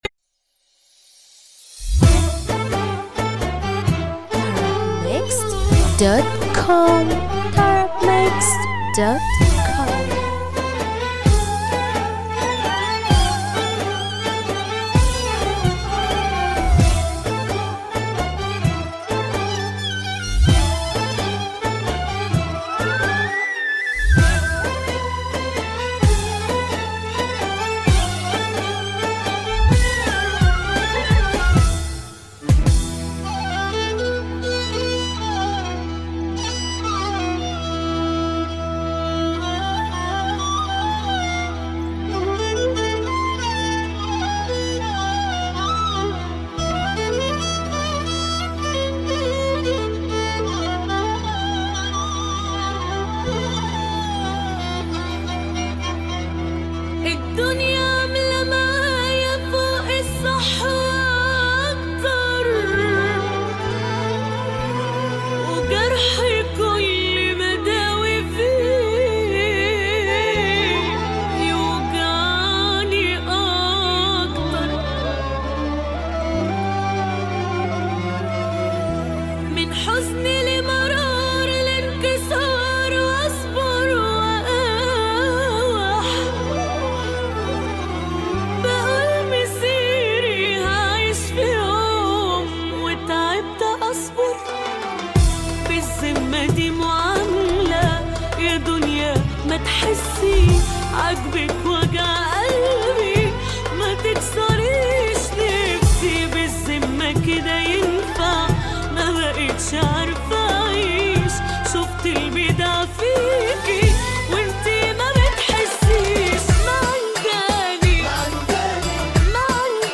• النوع : shobeiat